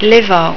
(pronuncia)   farina bianca, lievito di birra, acqua, sale Ne